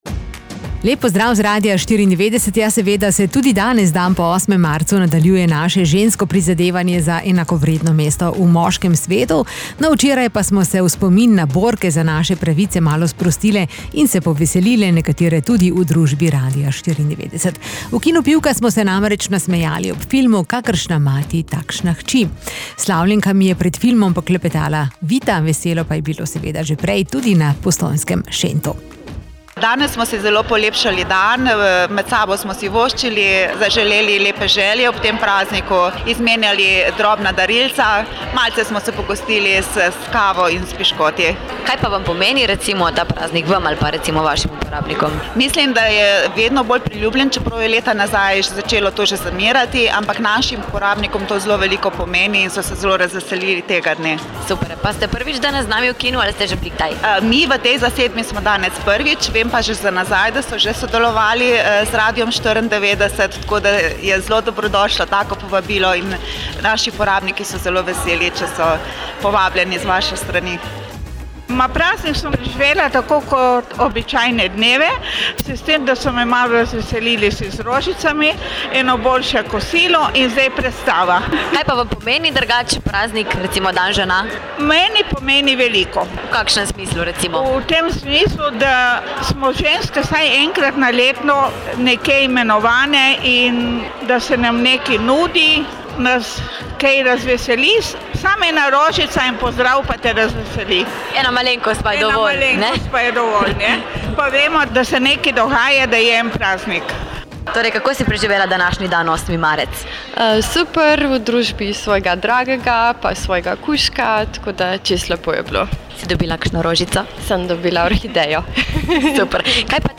• RADIJSKI DAN ŽENA V KINU PIVKA
kino-pivka-osmi-marec-anketa.mp3